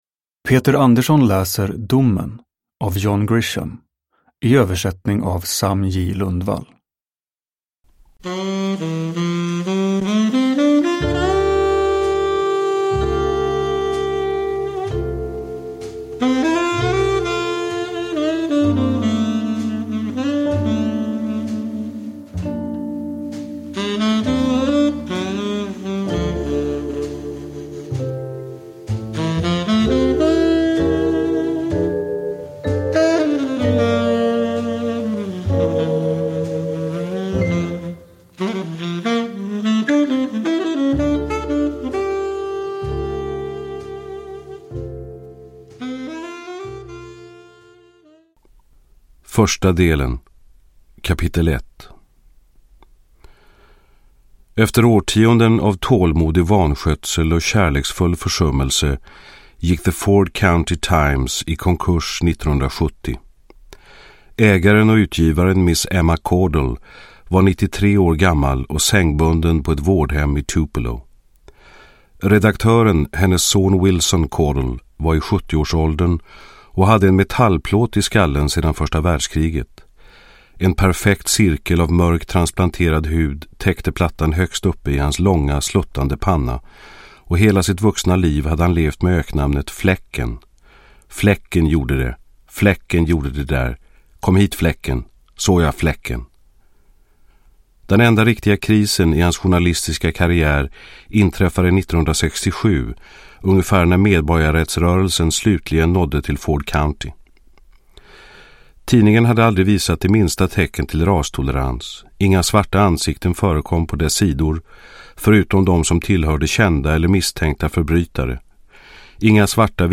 Domen – Ljudbok
Uppläsare: Peter Andersson